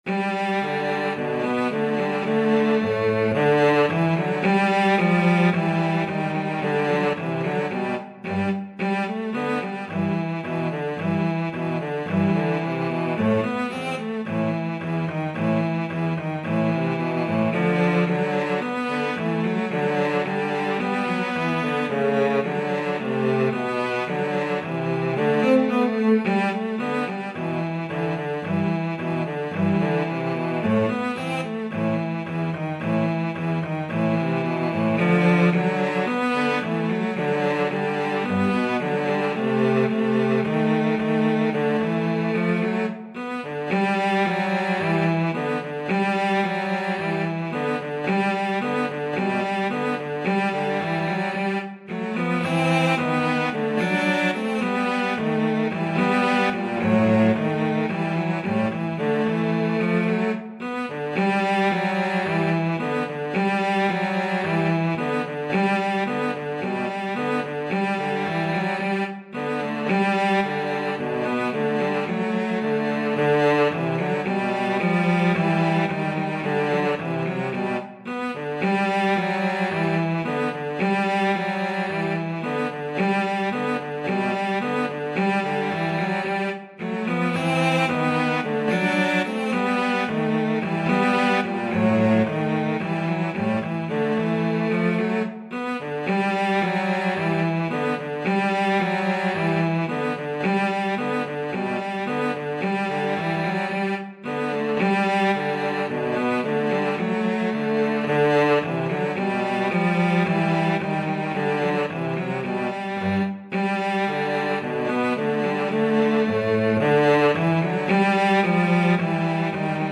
2/4 (View more 2/4 Music)
Moderato allegro =110
Cello Duet  (View more Easy Cello Duet Music)